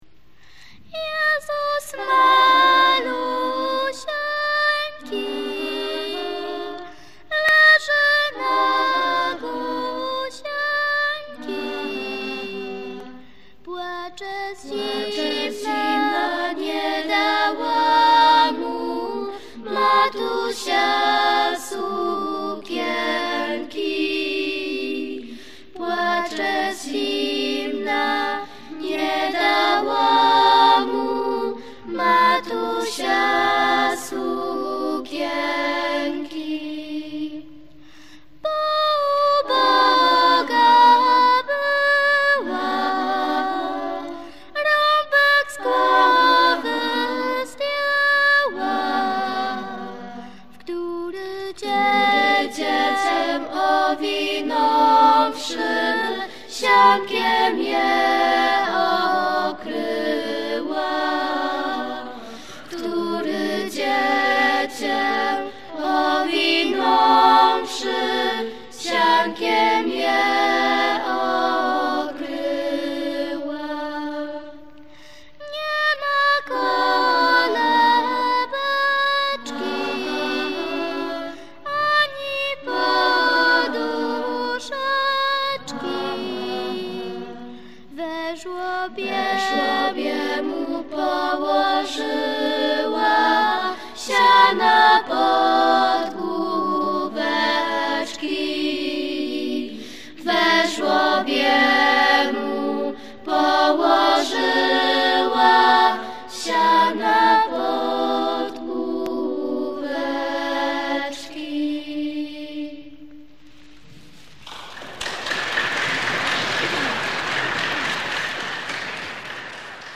Nagrania live, utwory w formacie mp3 (96kbps),
zarejestrowane na koncertach w Jasieniu i w Domecku.